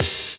OpenHH Shooter 3.wav